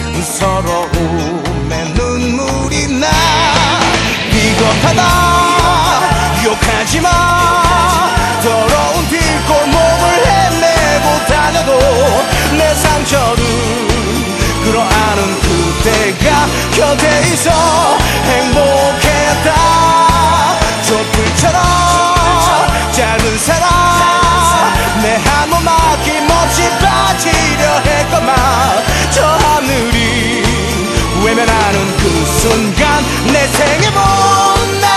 1998-01-01 Жанр: Поп музыка Длительность